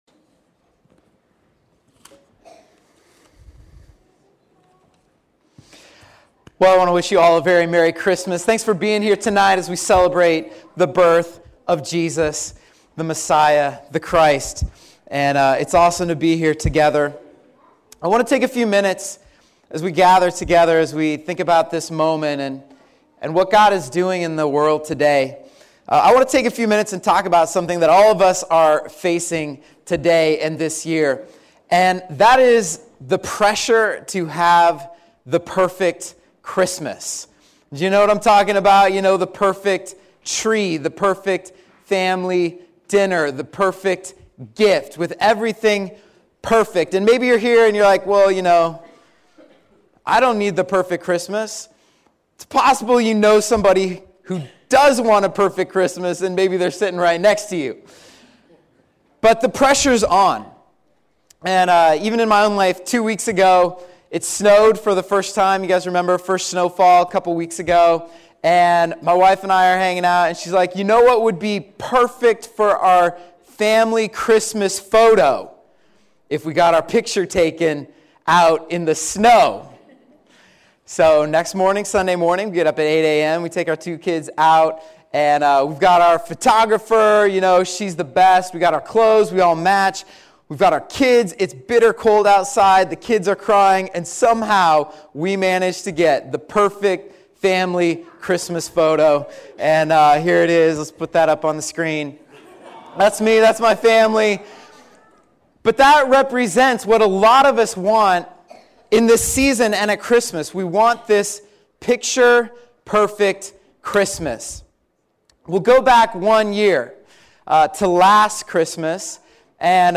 Christmas Eve message 2017